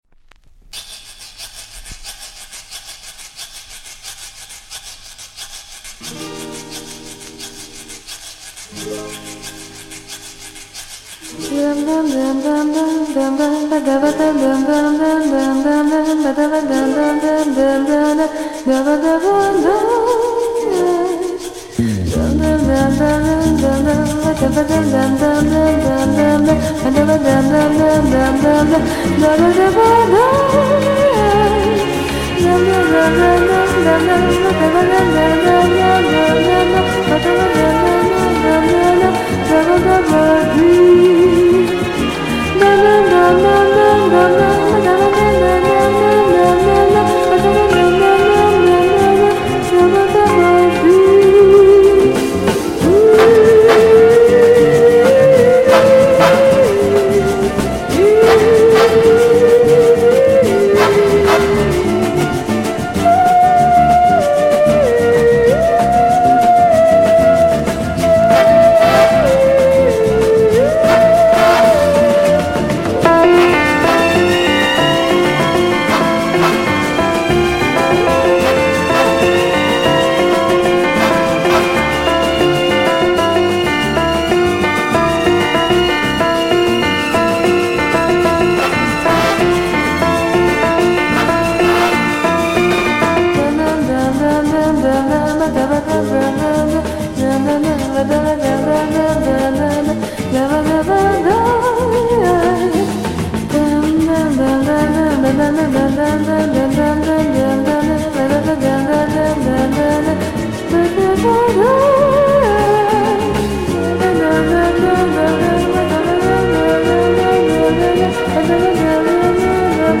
Bossa / Samba spain
スペインの女性シンガー